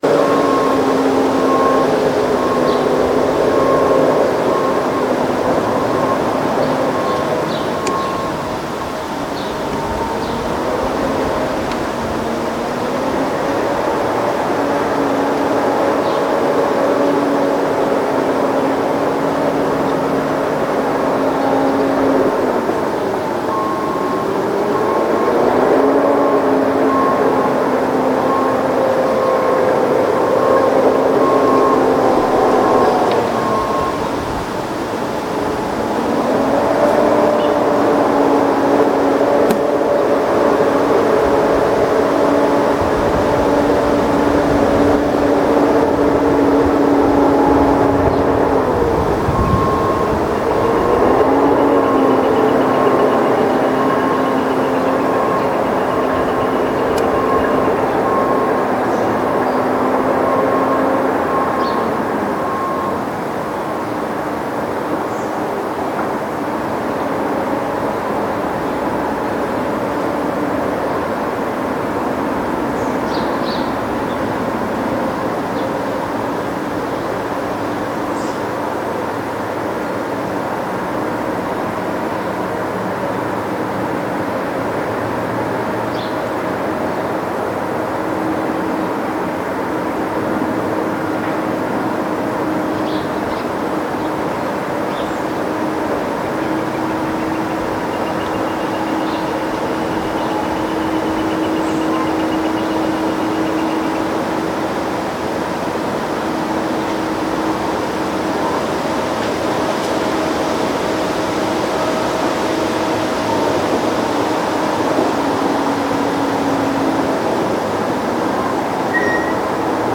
Dump-truck-noise-and-beeping-trimmed.m4a